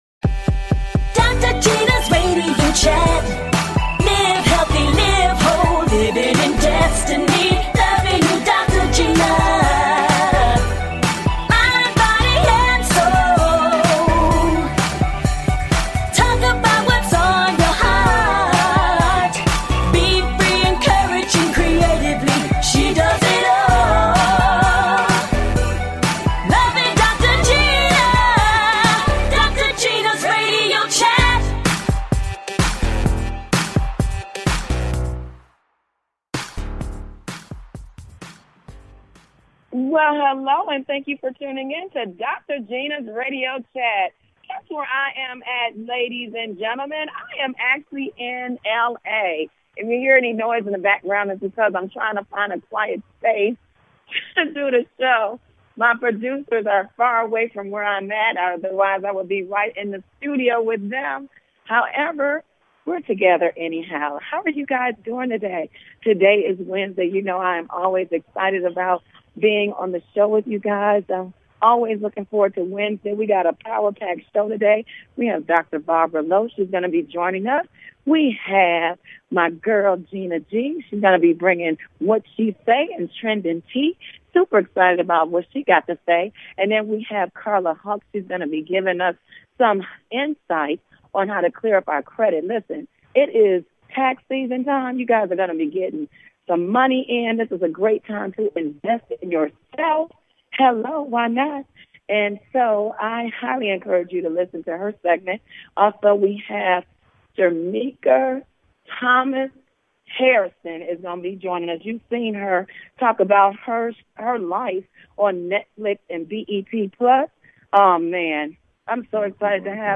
A talk show of encouragement.